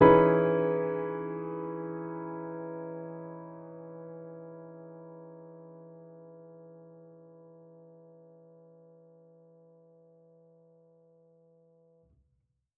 Index of /musicradar/jazz-keys-samples/Chord Hits/Acoustic Piano 1
JK_AcPiano1_Chord-Emaj13.wav